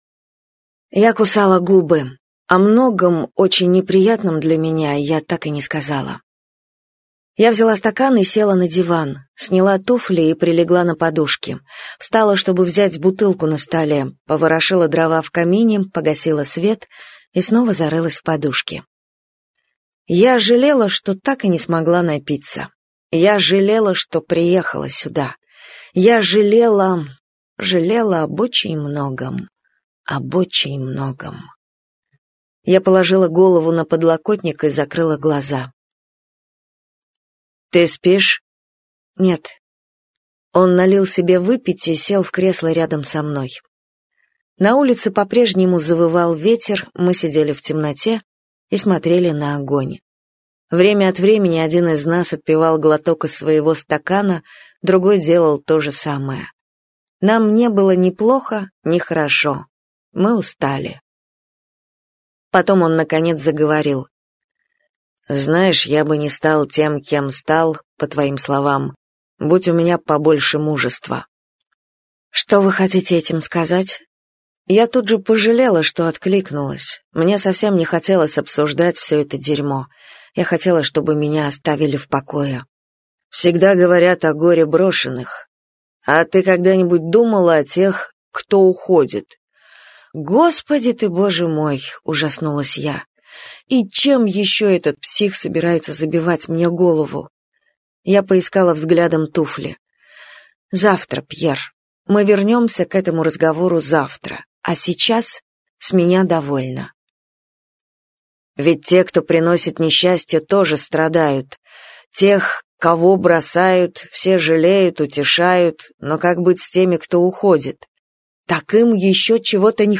Аудиокнига Я ее любил.